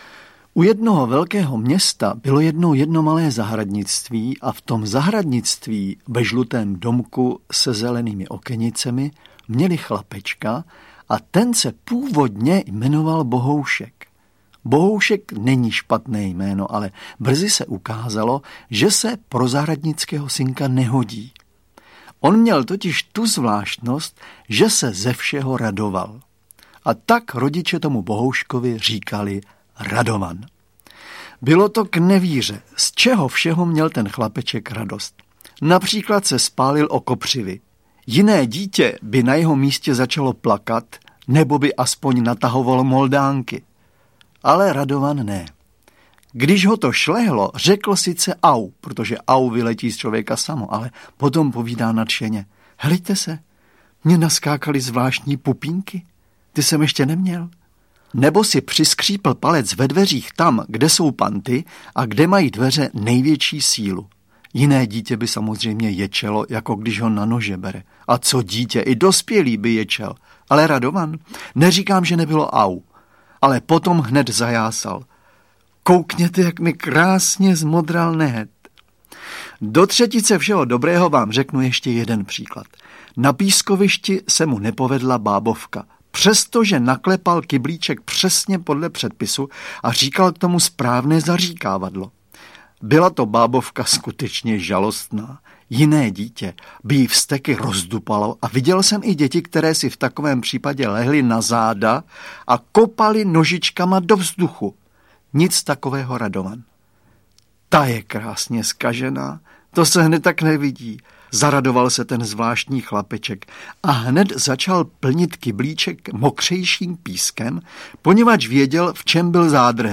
Radovanovy radovánky audiokniha
Audiokniha Radovanovy radovánky, kterou napsal a namluvil Zdeněk Svěrák.
Ukázka z knihy
• InterpretZdeněk Svěrák